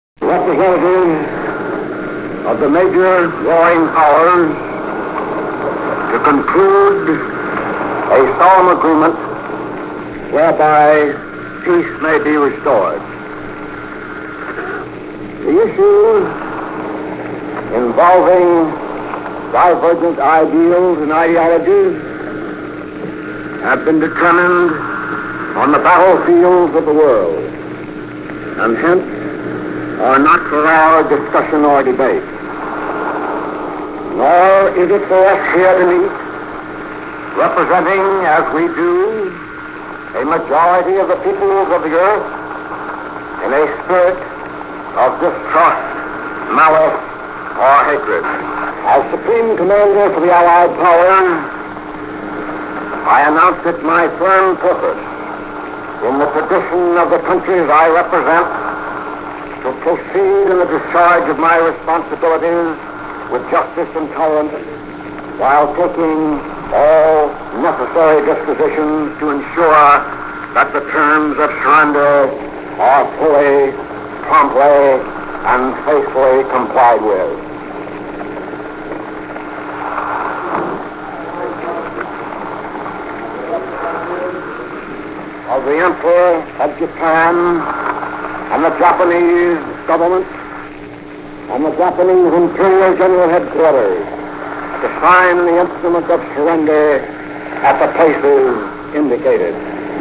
macarthur-surrender.wav